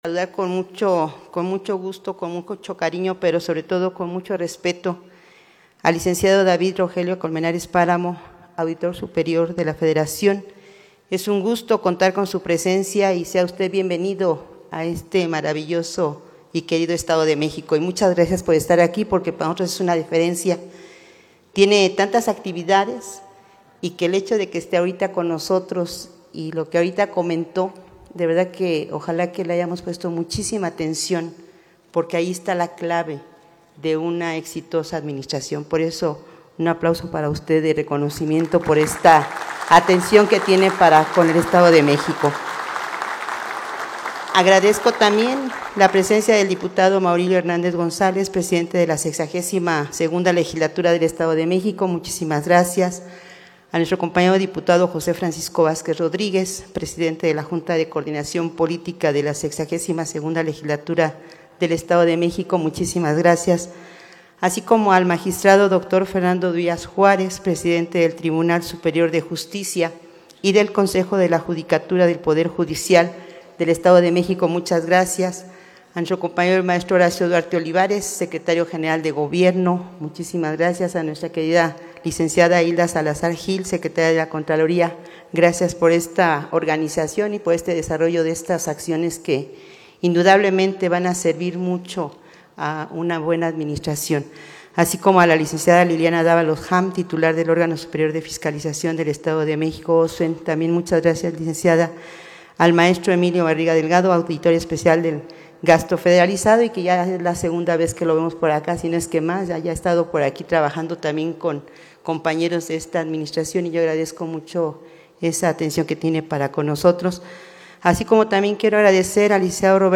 Inaugura Gobernadora Delfina Gómez Álvarez seminario de fiscalización para mejorar la rendición de cuentas y el uso de los recursos públicos TOLUCA…
AUDIO-MENSAJE-COMPLETO_DGA_SEMINARIO-FISCALIZACION-AVANCES-Y-RETOS-EN-EL-ESTADO-DE-MEXICO.mp3